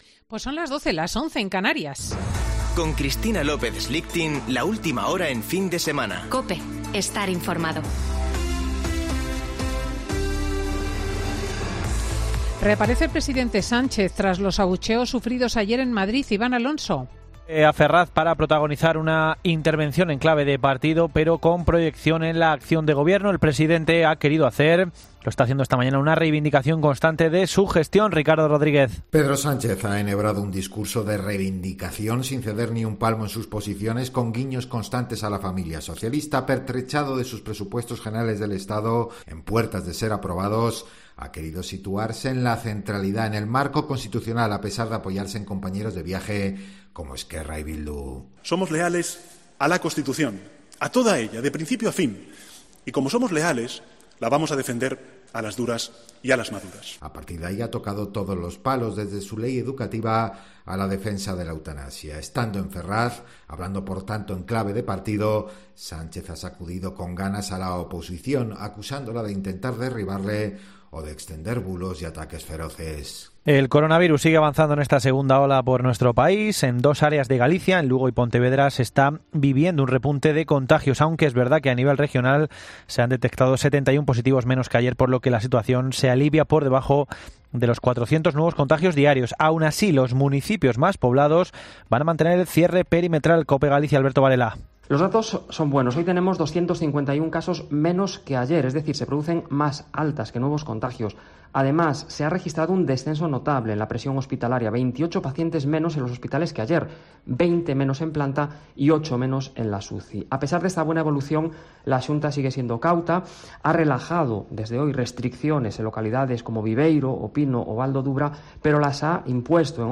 AUDIO: Boletín de noticias de COPE del 28 de Noviembre de 2020 a las 12.00 horas